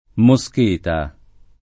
[ mo S k % ta ]